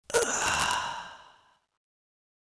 Breath 02
Breath 02.wav